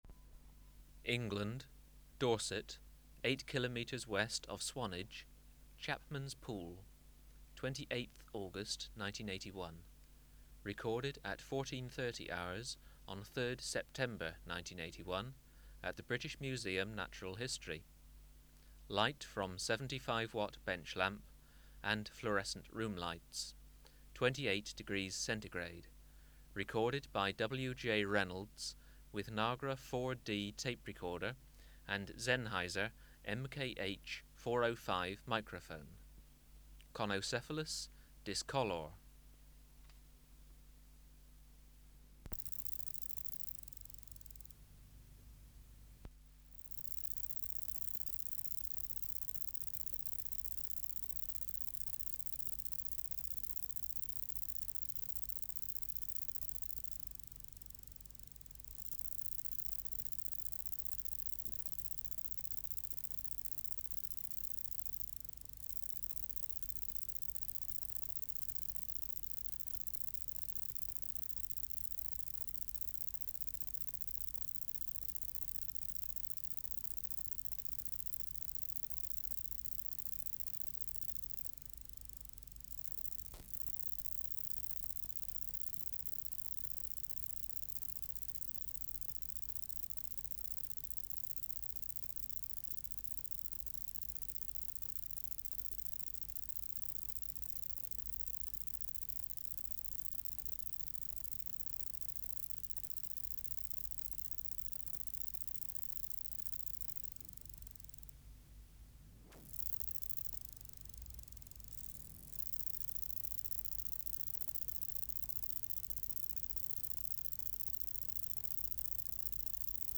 Conocephalus discolor
Voice Introduction